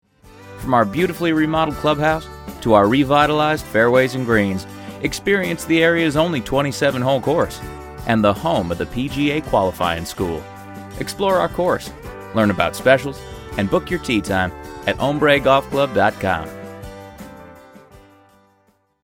Demo
Adult, Young Adult
Studio Specs: Neumann TLM 103 Mic // Focusrite ISA One // Apple Logic Studio Software // Macbook Pro
southern us
Southern.mp3